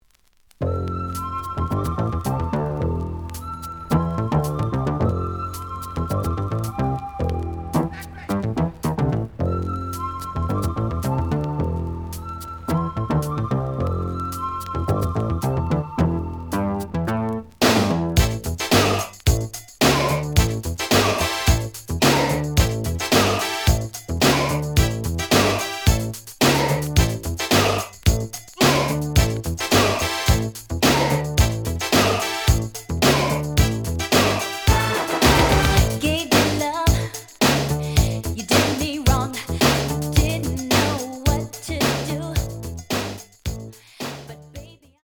The audio sample is recorded from the actual item.
●Format: 7 inch
●Genre: Hip Hop / R&B